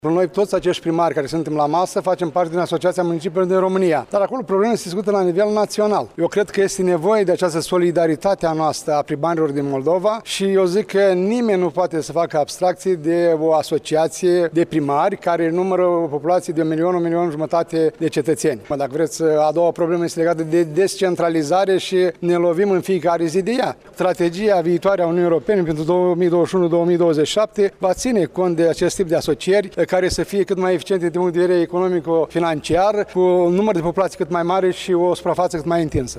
Primarul municipiului Suceava, Ion Lungu, a adăugat, la rândul său, că asocierile dintre localităţi sunt mult mai efeciente atunci când vine vorba de atragerea banilor europeni pentru dezvoltare: